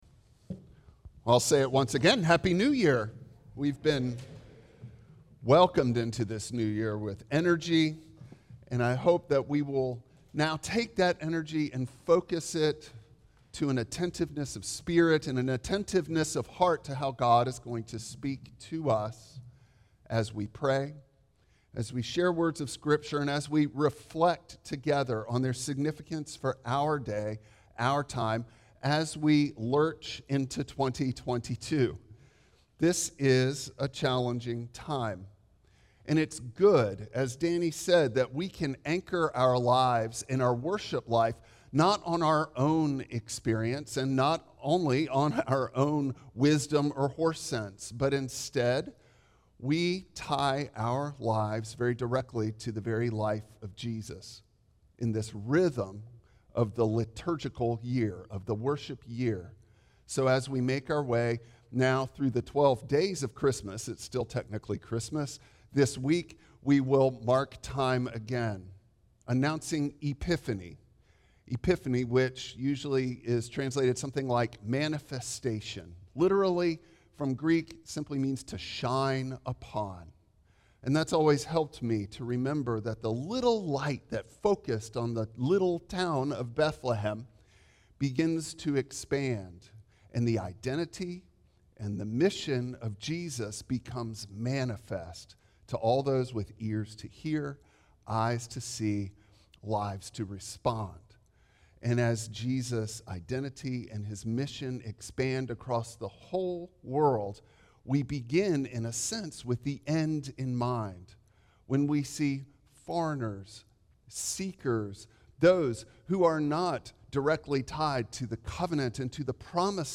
Passage: Matthew 2:1-12 Service Type: Traditional Service Bible Text